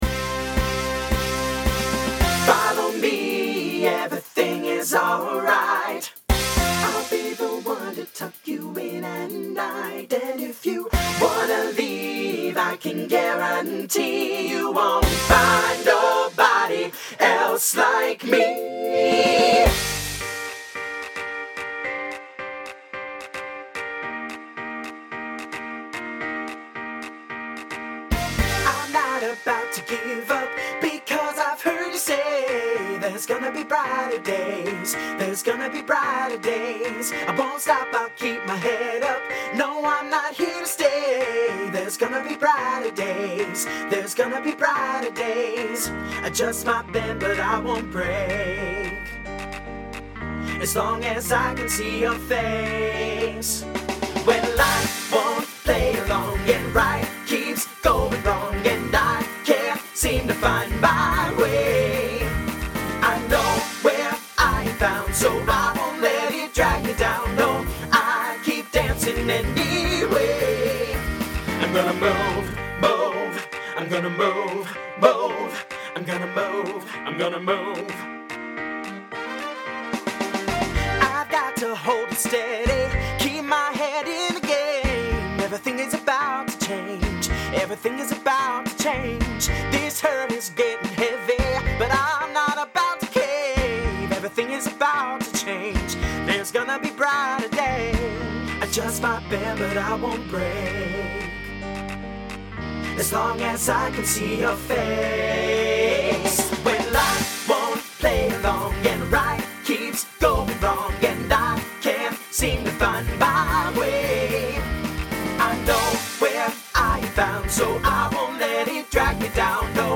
SATB/TTB
Instrumental combo
Pop/Dance